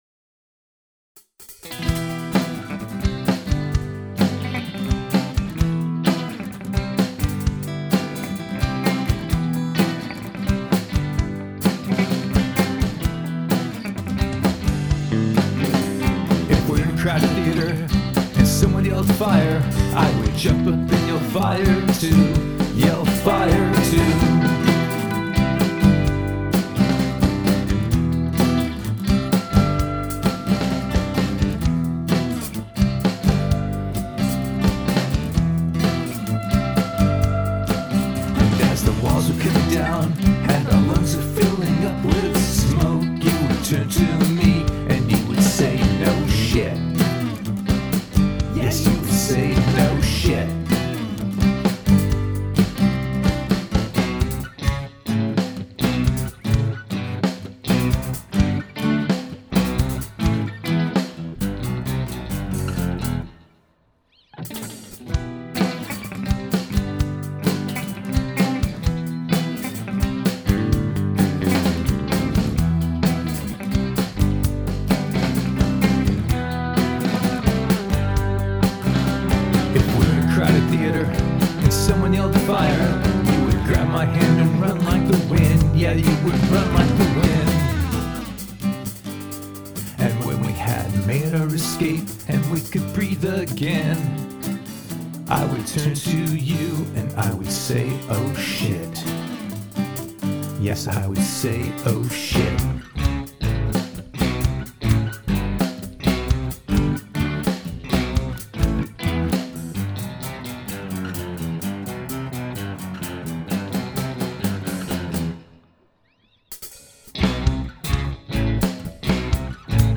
no_shit_129bpm.mp3